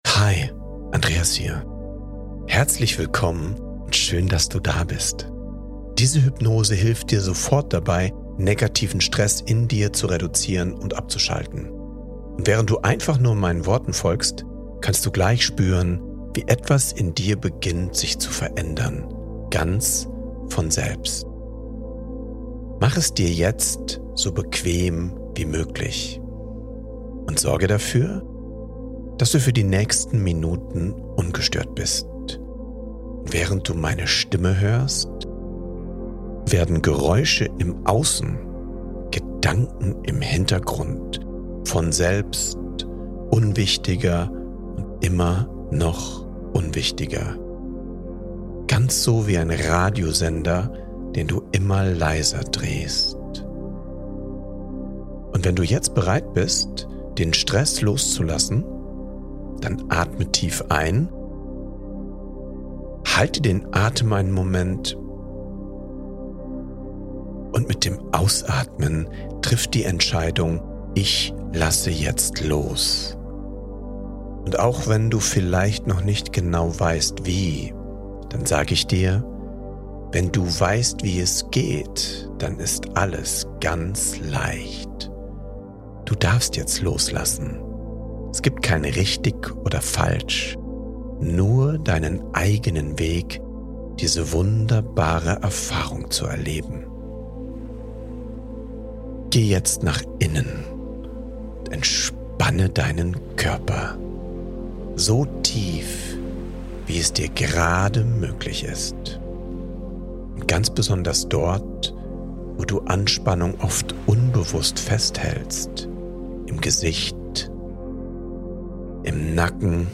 Soforthilfe bei Stress | 8 Minuten geführte Hypnose zur schnellen Entspannung